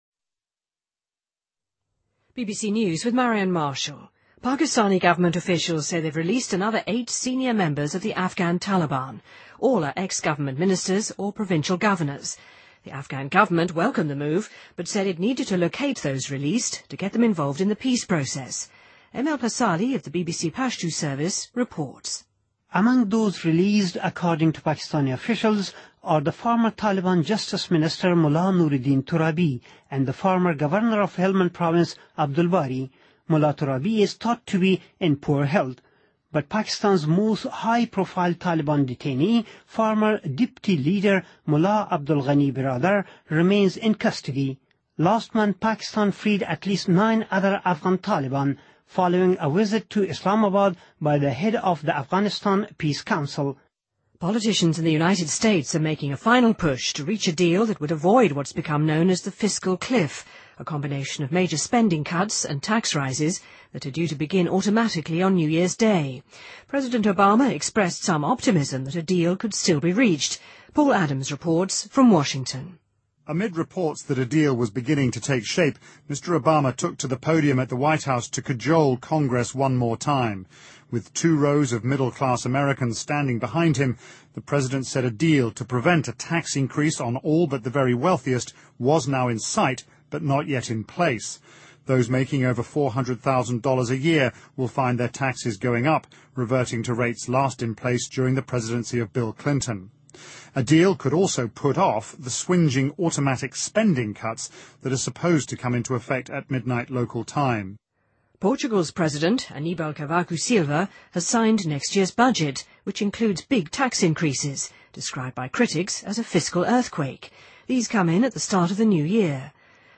Date:2013-01-01Source:BBC Editor:BBC News